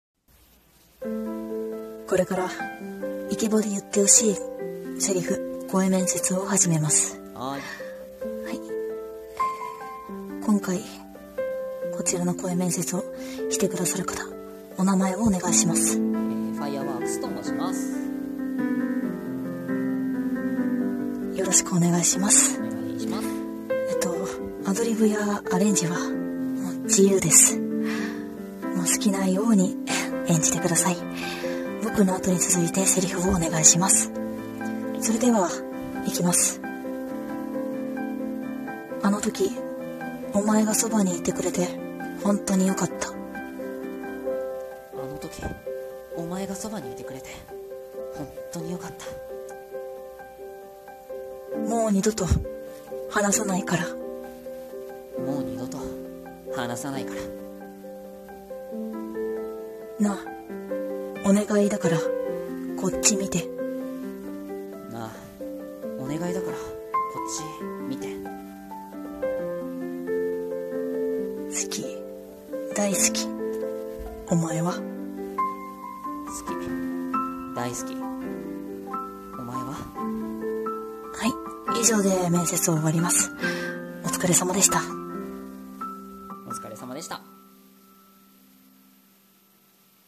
[声面接]イケボで言ってほしい台詞。